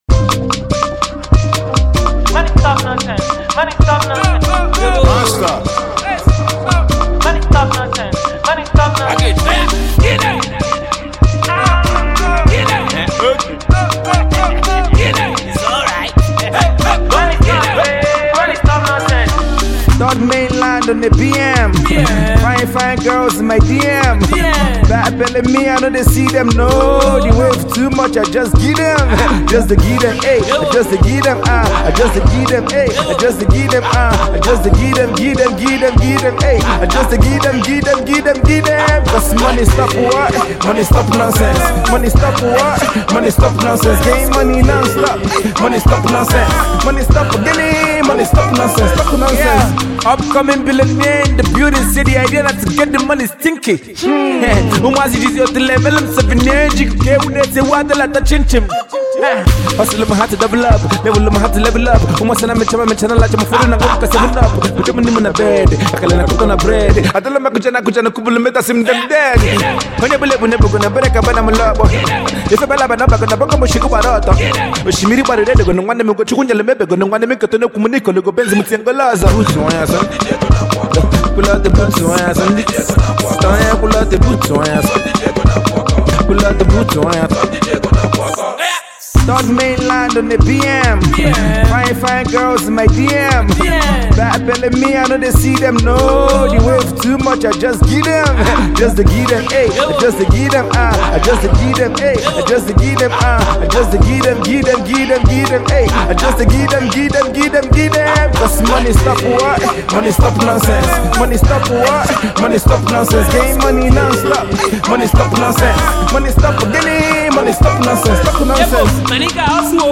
Nigerian Indigenous rapper
catchy single